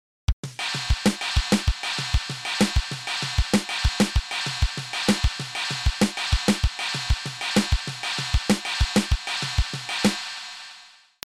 Linear Two resulted from a continuation of the experiment I began in Linear One: I created 4 separate 4-beat rhythms for kick and snare and played them under a steady cymbal figure. In this case, the ride pattern is a simple offbeat on the china.
This was one of the first grooves in 7 that I created after not playing for a year or two. The ride pattern alternates between cymbals and takes two bars to resolve — that is, if you start with 1 on the bell, the following 1 will be on the china because there are 7 beats to the bar.